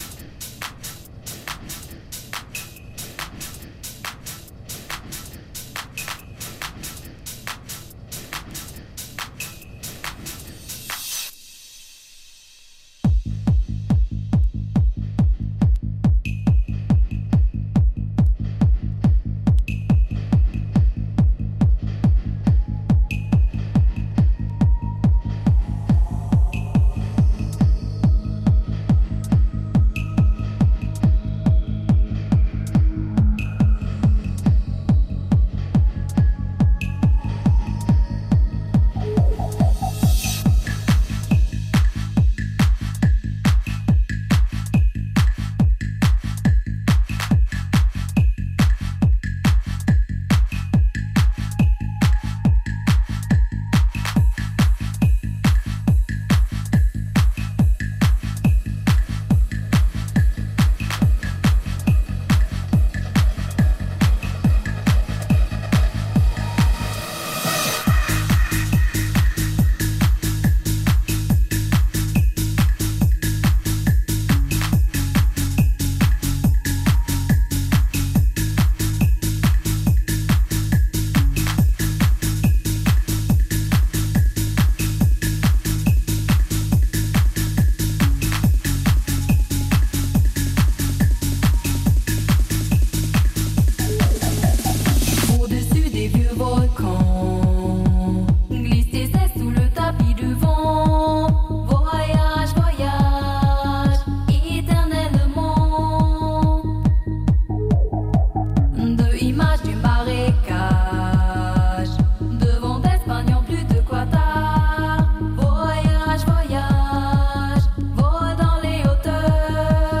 Genre: Club.